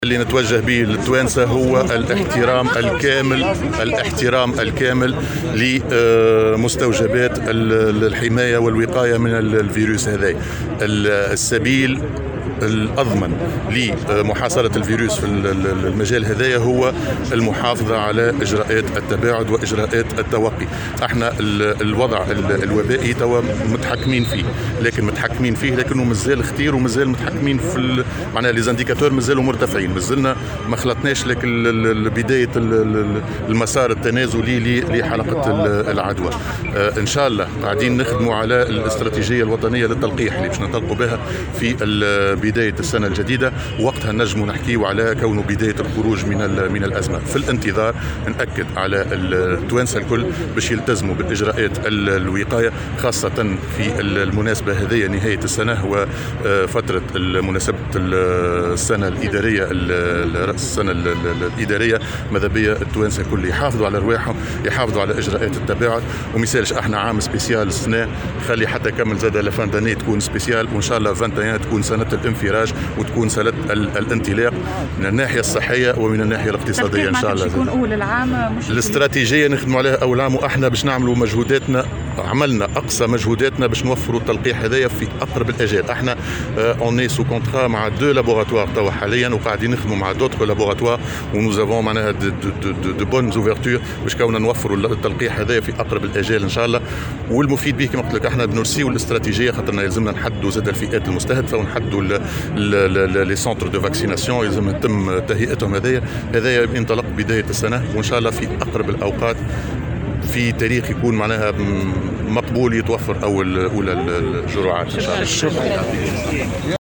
على هامش انطلاق الحملة الوطنية لصيانة المدارس